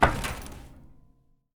metal_plate2.ogg